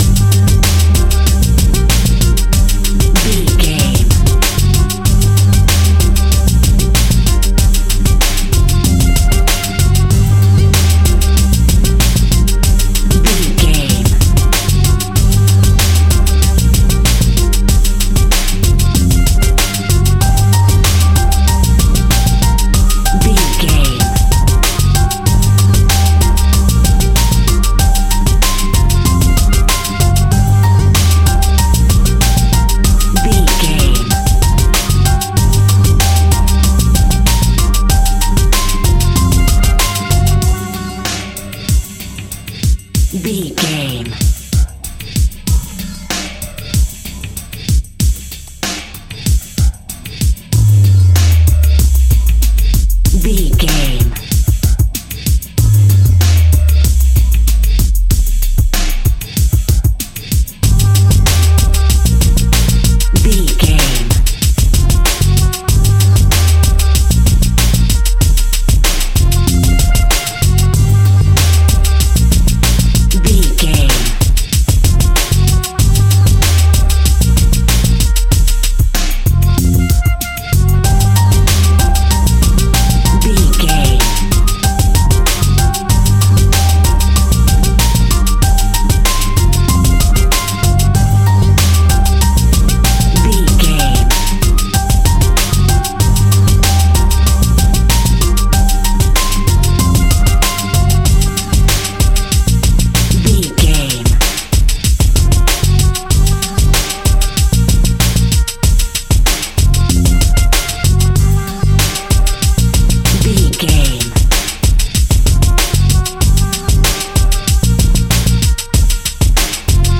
Hip Hop Grime.
Aeolian/Minor
hip hop instrumentals
funky
groovy
east coast hip hop
electronic drums
synth lead
synth bass